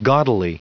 Prononciation du mot gaudily en anglais (fichier audio)
Prononciation du mot : gaudily